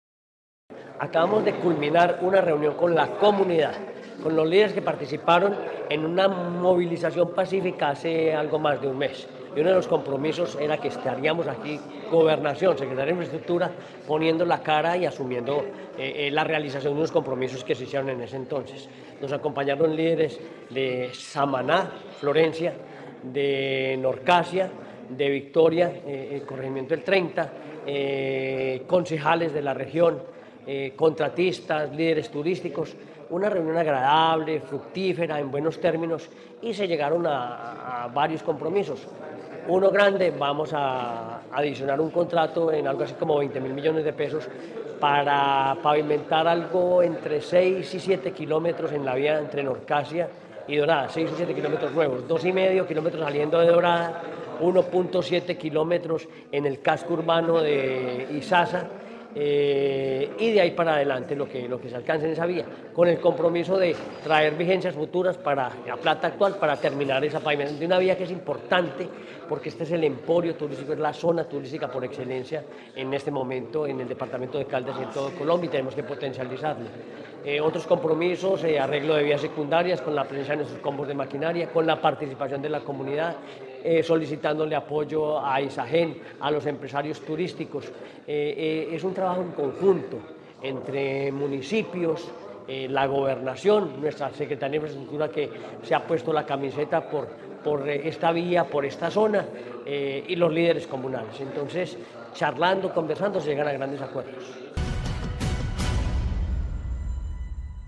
Henry Gutiérrez Ángel, gobernador de Caldas